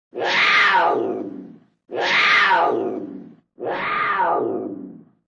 Jaguar
Ecoutez son cri
jaguar.wma